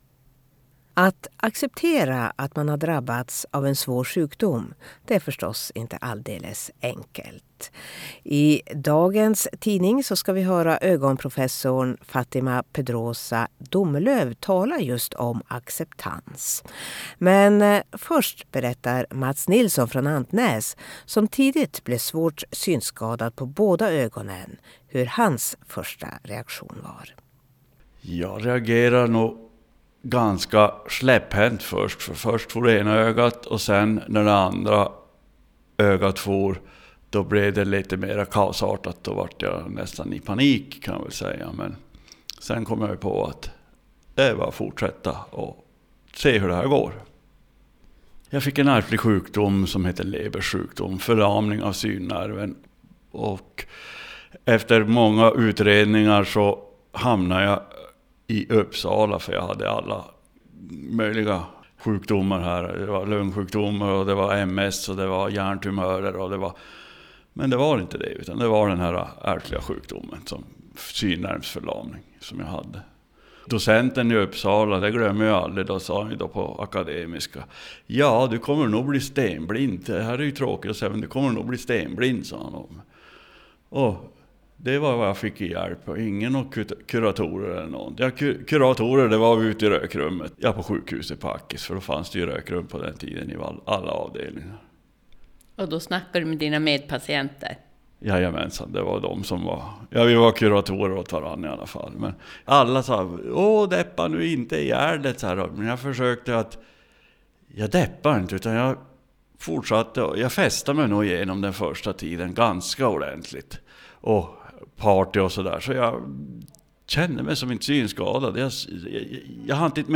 Hör arbetsmarknadsminister Eva Nordmark kommentera uttalandet.